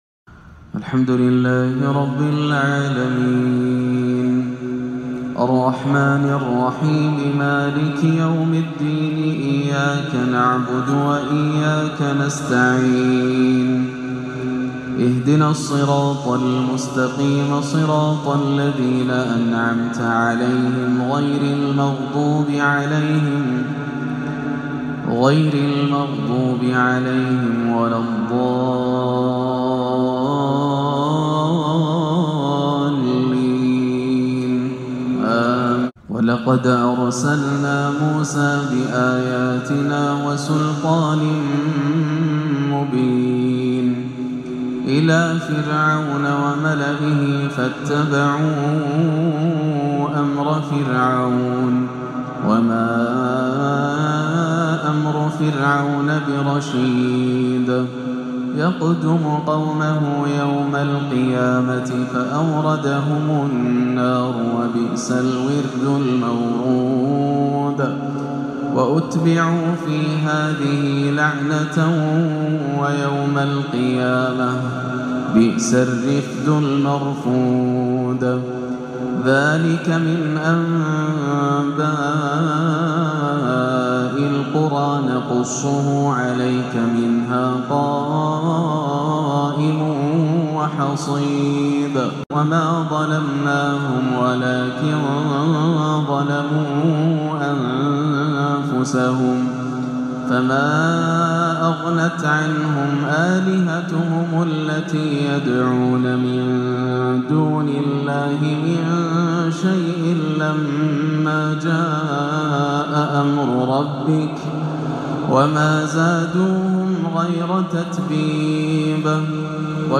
[ فأما الذين شقوا ففي النار ] تلاوة خاشعة من سورة هود - الإثنين 4-4-1438 > عام 1438 > الفروض - تلاوات ياسر الدوسري